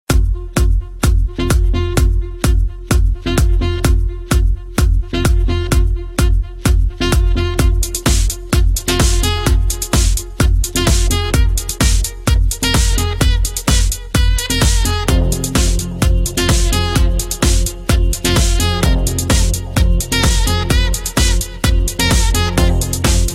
Thể loại nhạc chuông: Nhạc DJ